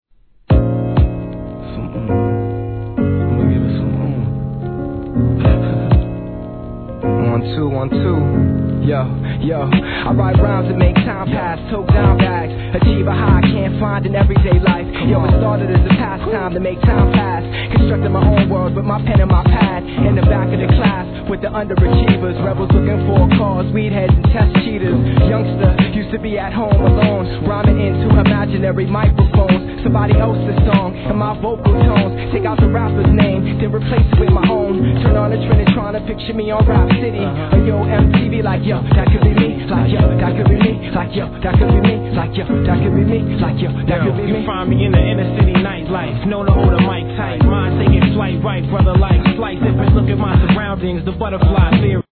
HIP HOP/R&B
疾走感溢れるBEATにシンプルなLOOPがカッケ〜す!!